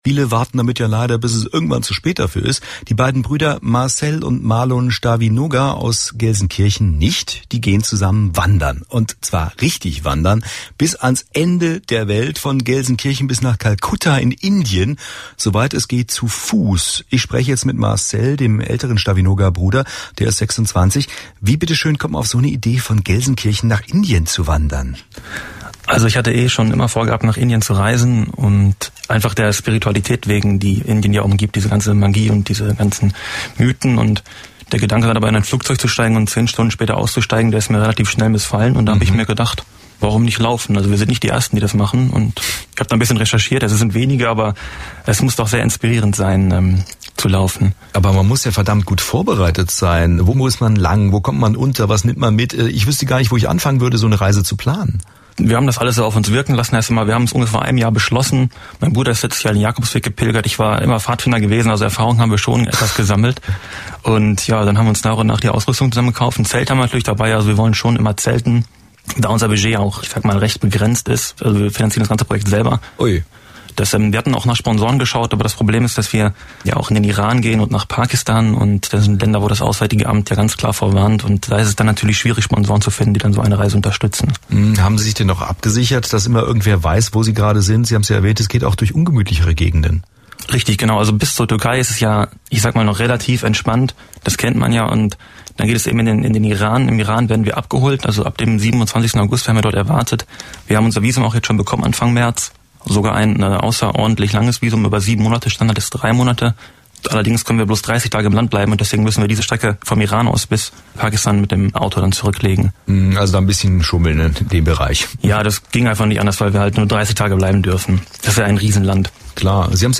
Interview WDR2